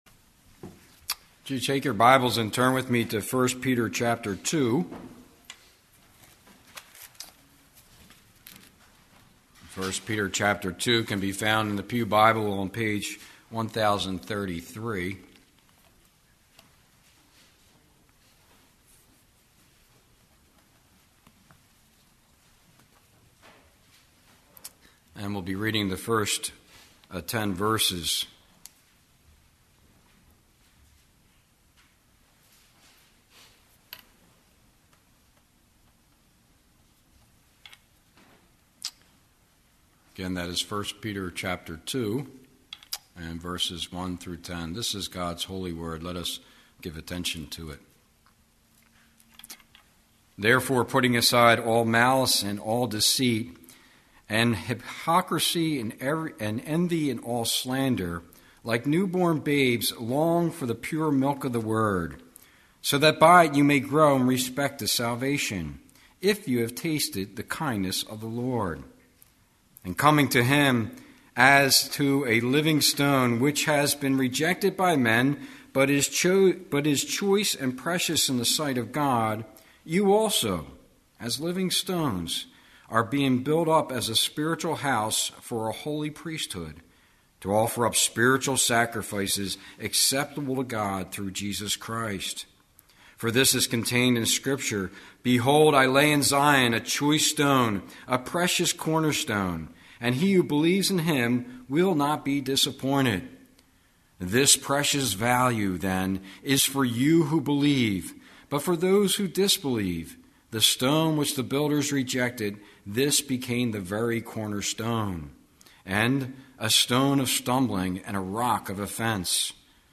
Service Type: Sunday Evening